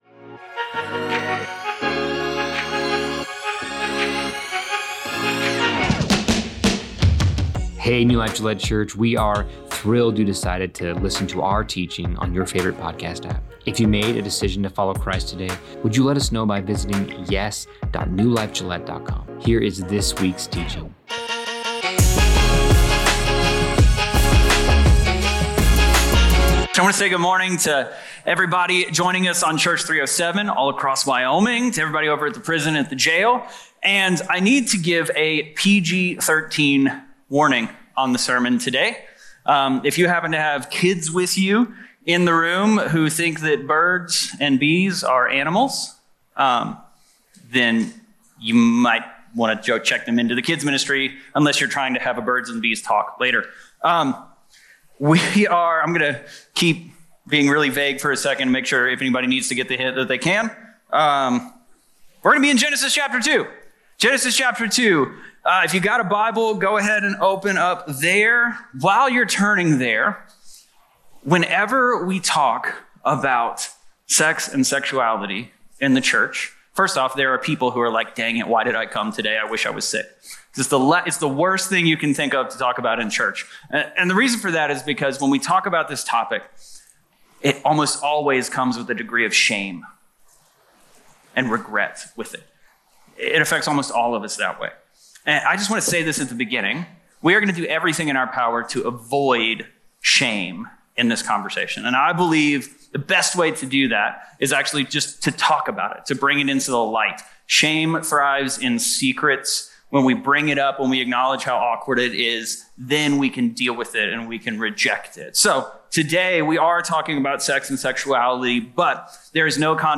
This message covers: